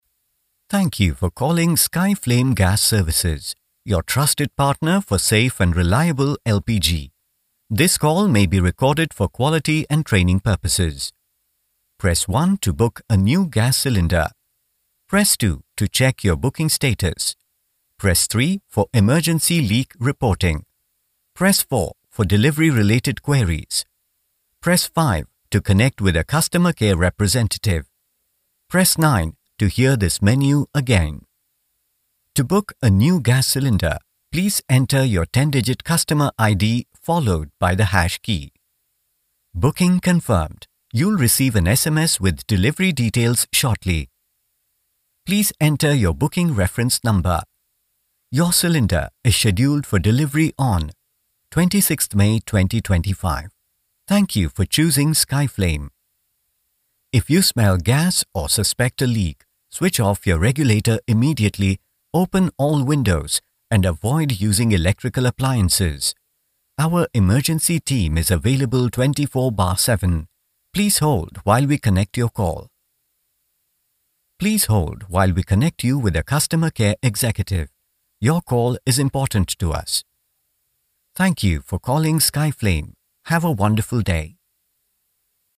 Male
Warm, friendly, soothing voice with a good balance of bass and clear high end.
Middle-aged Neutral English accent tilted towards British/Indian with clear enunciation, and judicious pace.
Phone Greetings / On Hold
Ivr For An Indian Company
1202IVR.mp3